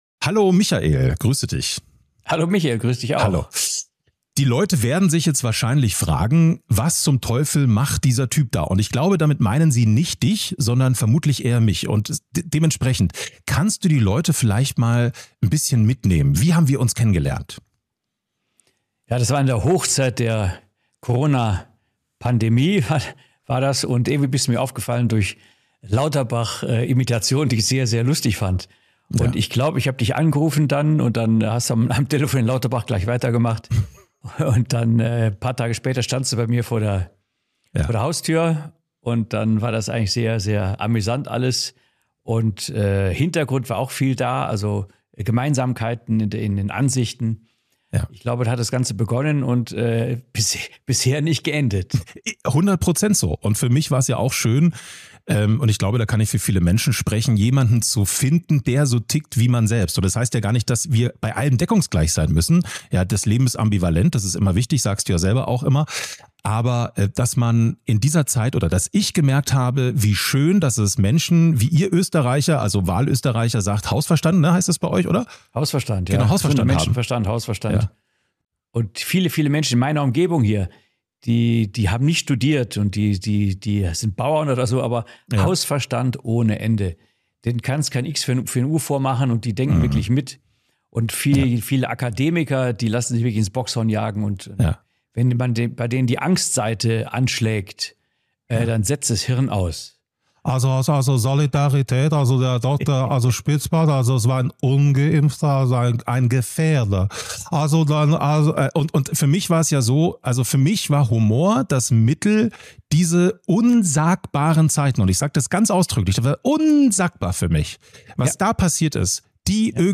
Sie ist ein ehrliches Gespräch über Gesundheit, Verantwortung und Haltung – mit Klarheit, aber ohne Dogma.